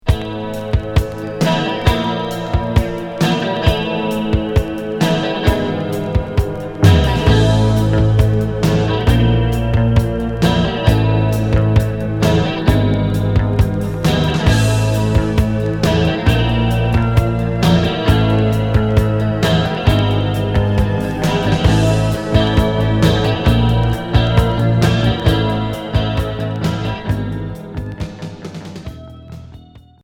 Cold wave Unique 45t retour à l'accueil